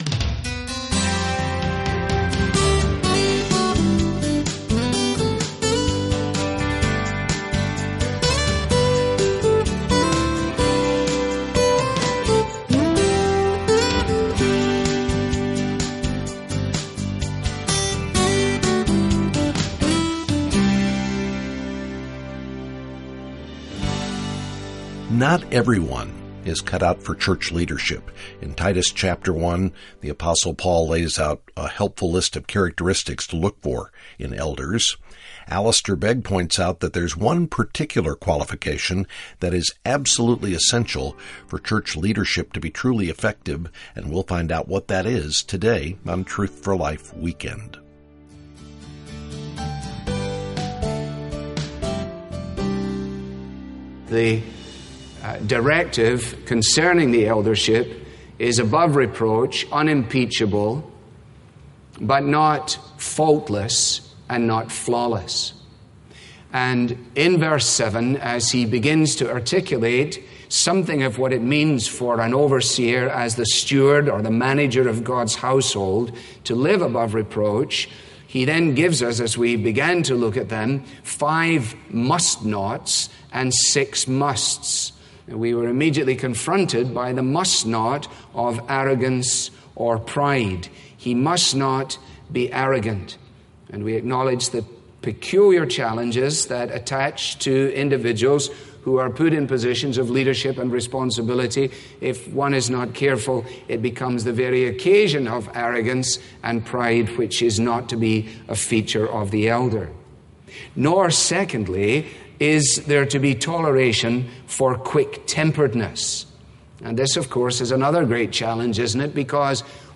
Helpful Resources - Learn about God's salvation plan - Read our most recent articles - Subscribe to our daily devotional Follow Us YouTube | Instagram | Facebook | Twitter This listener-funded program features the clear, relevant Bible teaching of Alistair Begg.